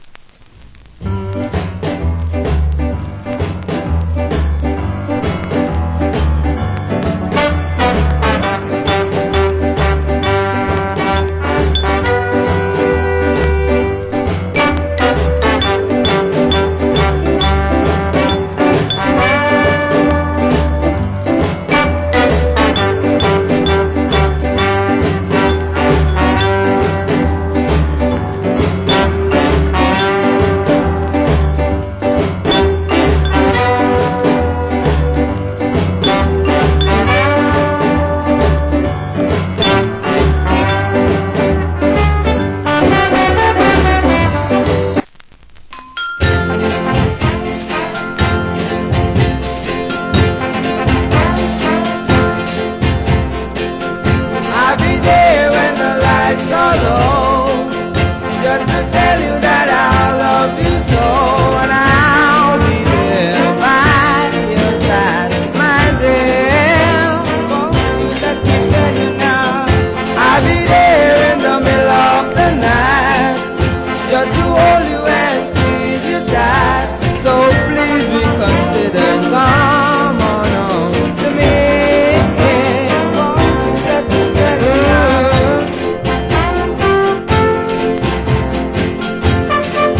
Foundation JA Shuffle Inst.